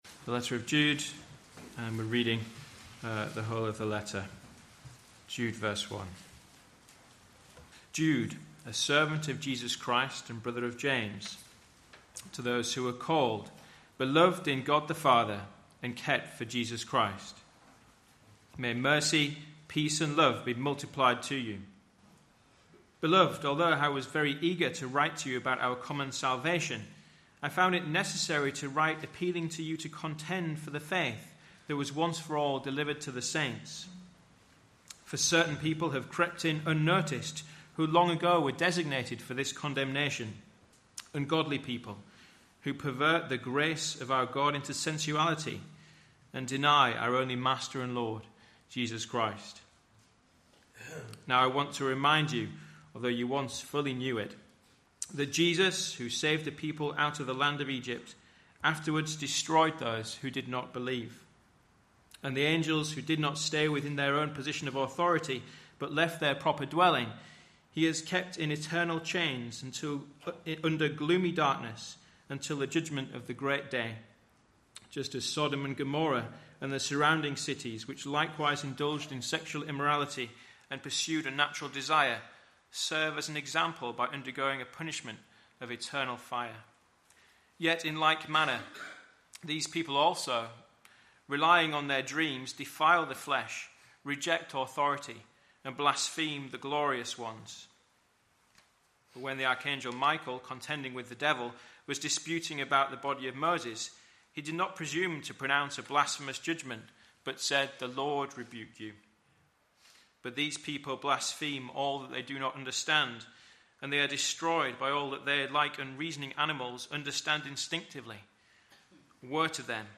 For example, how can we defend the historical truth of Jesus’ death and resurrection and the exclusive claims of Christianity? In this lecture we return to the world of the early church and see how they engaged a sceptical world.